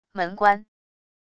门关wav音频